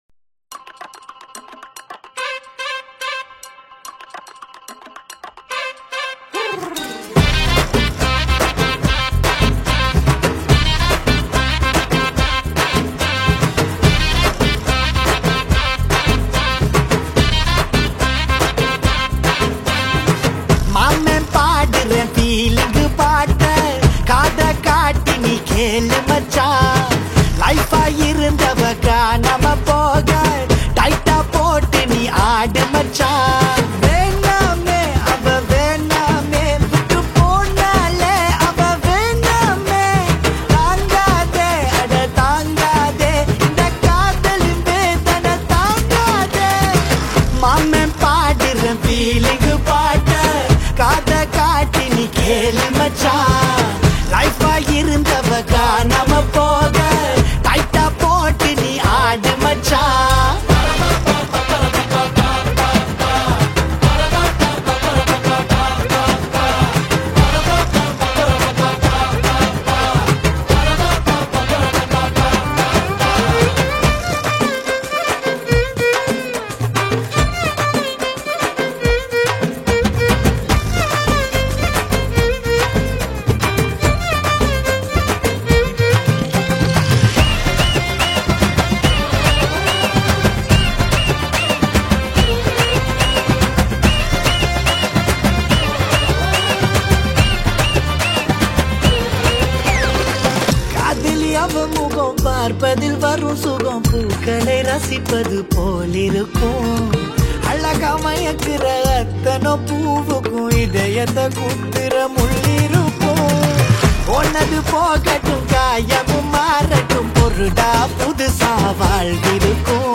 peppy and emotional track
Tamil Songs